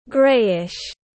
Màu hơi xám tiếng anh gọi là grayish, phiên âm tiếng anh đọc là /ˈɡreɪ.ɪʃ/.
Grayish /ˈɡreɪ.ɪʃ/